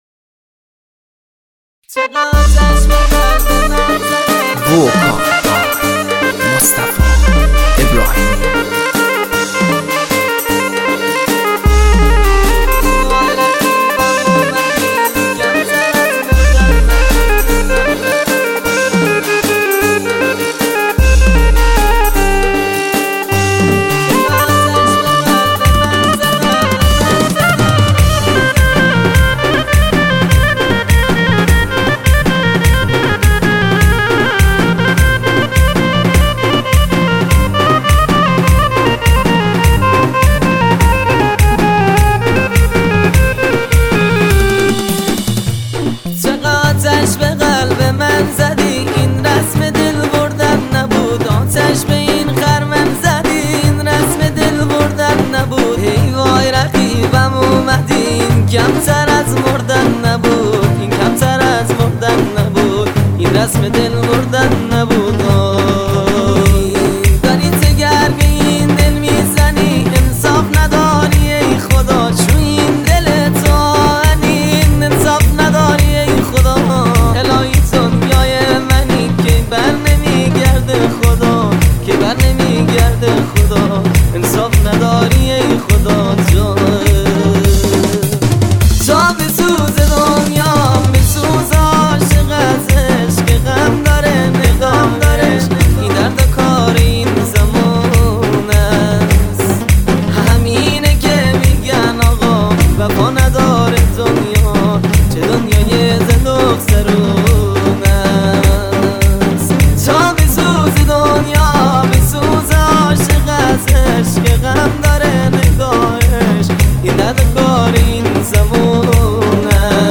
آهنگ غمگین و احساسی